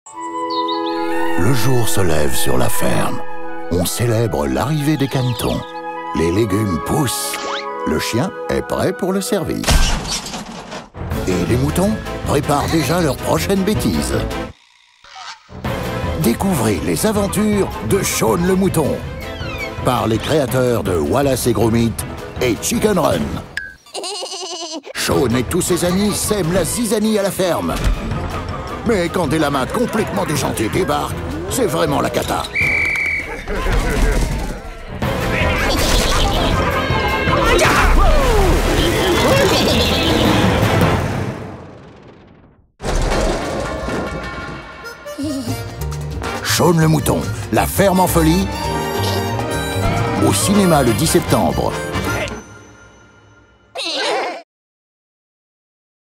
SHAUN-LE-MOUTON-LA-FERME-EN-FOLIE-Bande-annonce.mp3